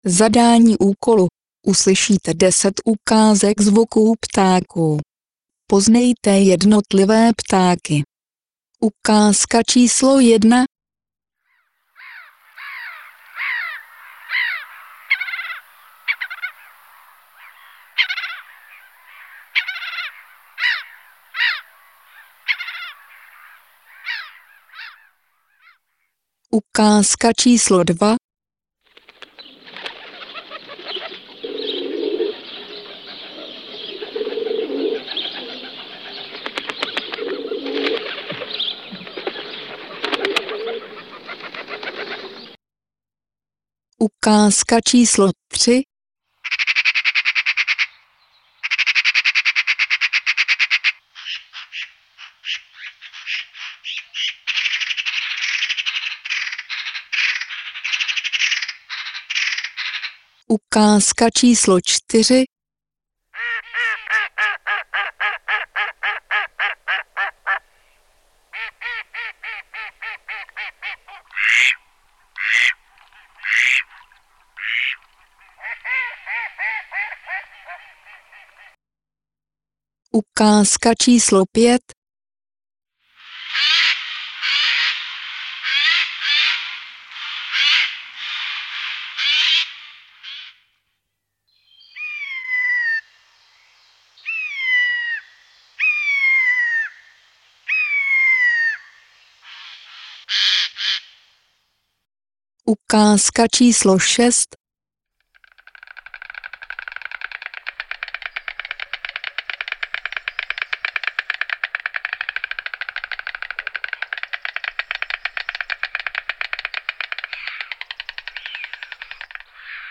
odpověď: 1. racek, 2. holub, 3. straka, 4. kachna, 5. sojka, 6. čáp, 7. vrabec, 8. kos, 9. vlaštovka, 10. hrdlička